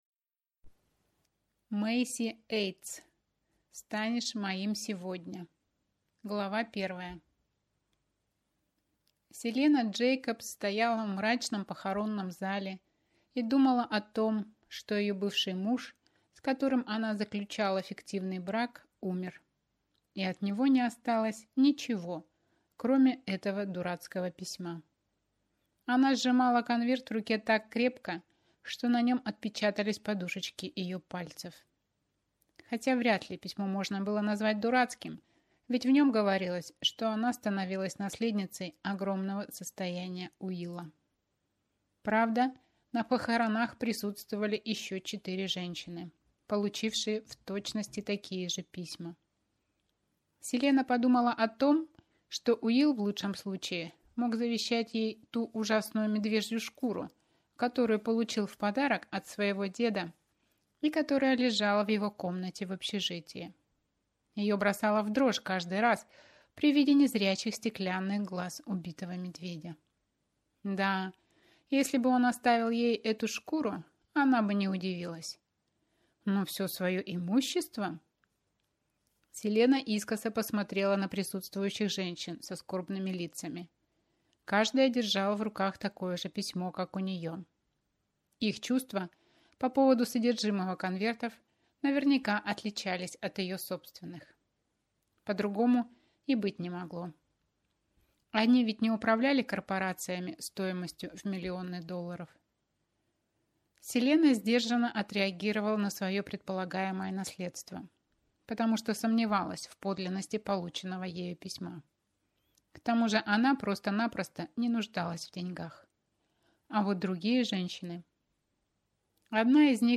Аудиокнига Станешь моим сегодня | Библиотека аудиокниг